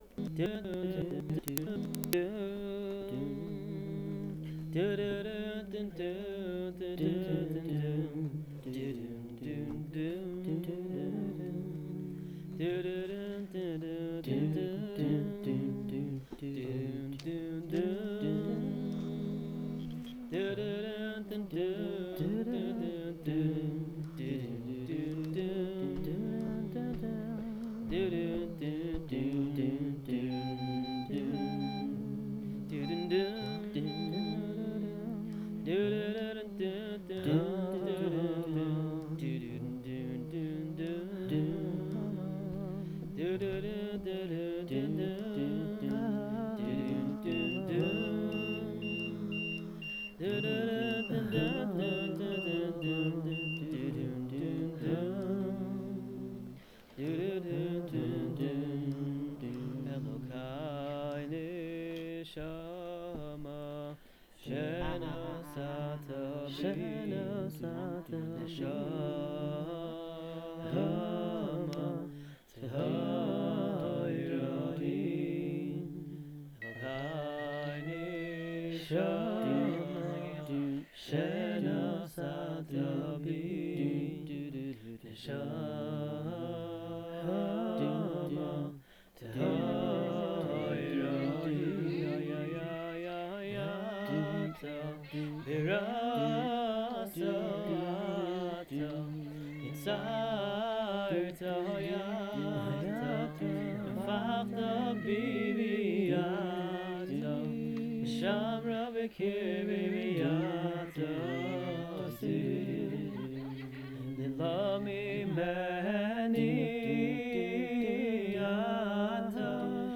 I composed this no-lyric tune right after I received rough news and I felt like I hit rock bottom – very hard.